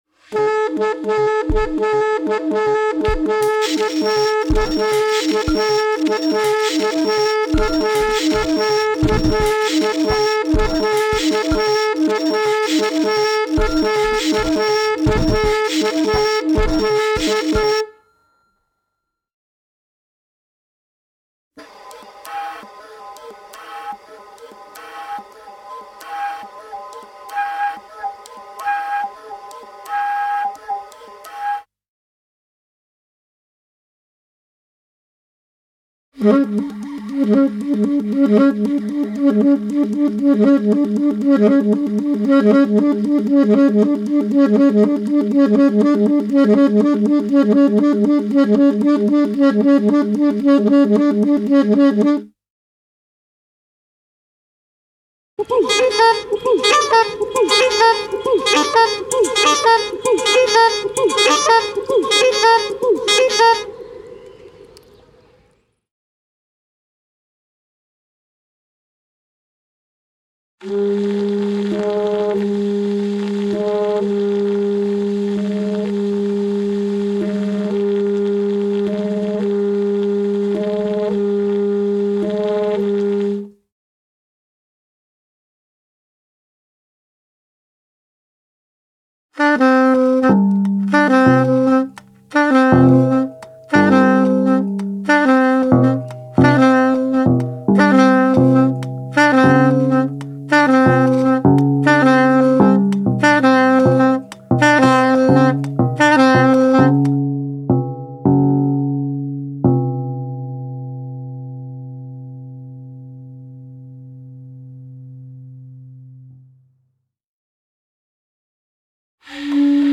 soprano & alto sax
inside piano & mixer
electronics
drums
double bass
guitar
percussion